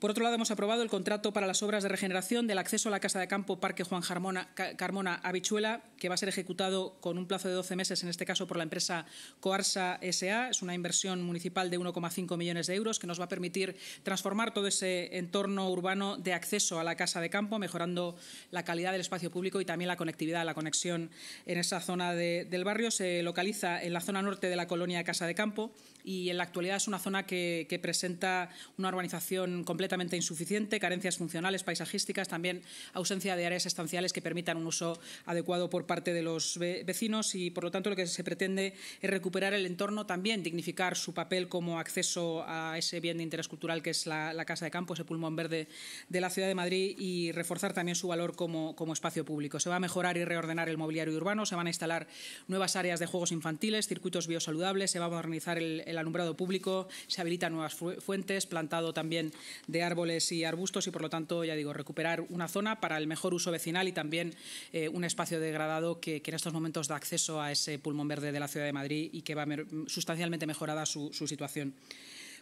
Nueva ventana:La vicealcaldesa y portavoz municipal, Inma Sanz